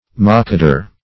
Search Result for " mockadour" : The Collaborative International Dictionary of English v.0.48: Mockadour \Mock"a*dour\, n. See Mokadour .